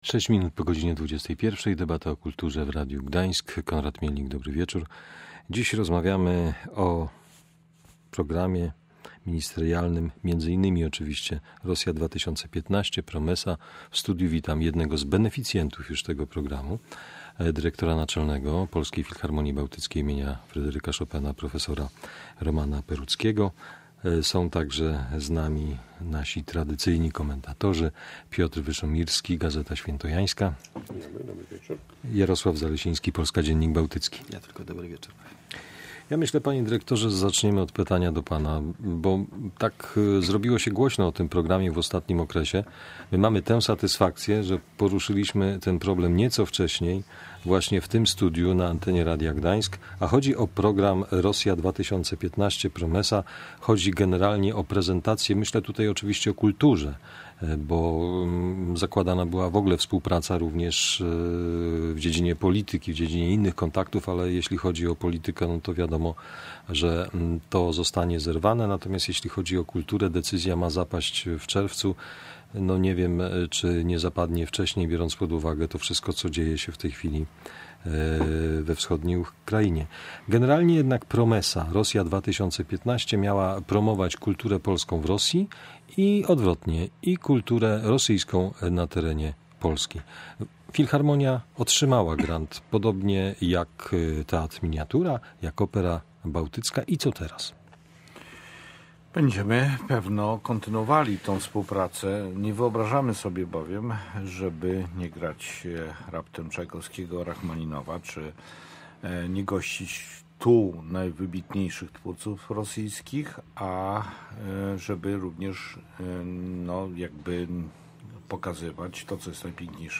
Goście rozmawiali o programie, który ma promować polską kulturę w Rosji.